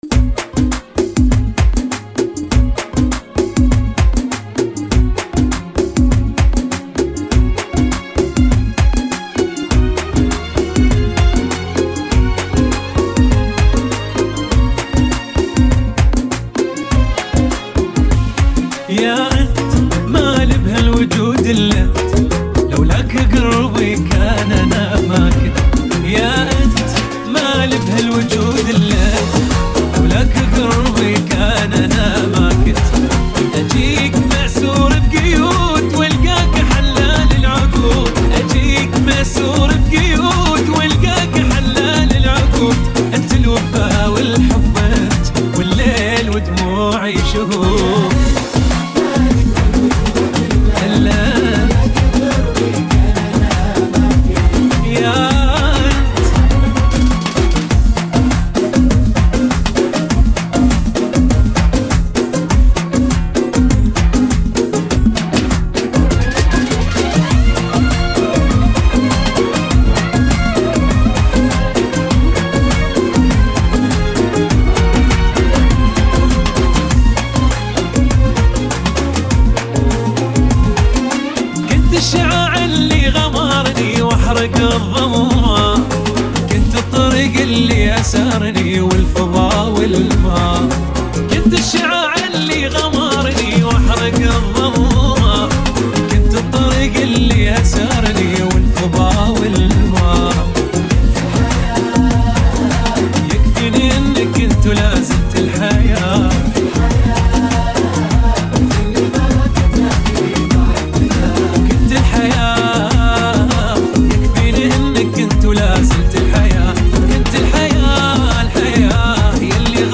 [ 100 bpm ] 2023